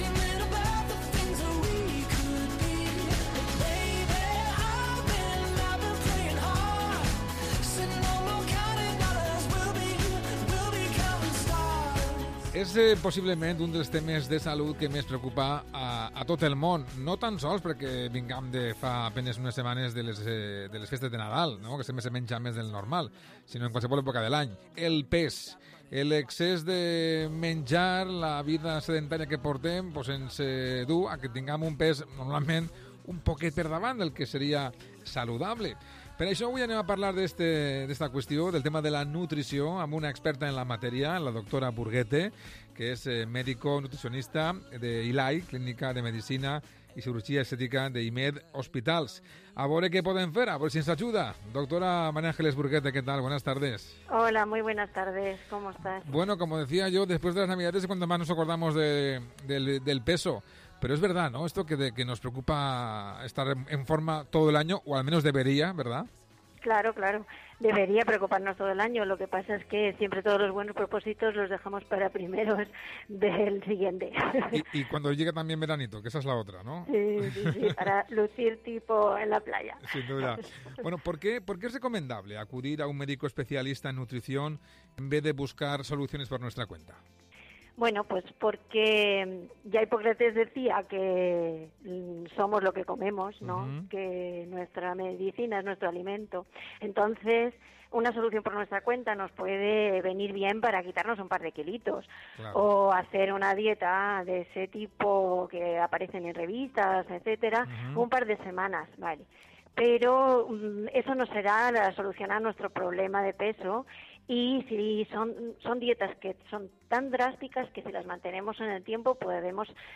Para la difusión de estas charlas, nuestros expertos en nutrición han realizado entrevistas en las emisoras de radio de merecido prestigio invitado a todos los oyentes a éstas y aportando su punto de vista sobre la alimentación saludable.
Entrevista en COPE Valencia